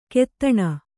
♪ kettaṇa